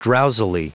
Prononciation du mot : drowsily
drowsily.wav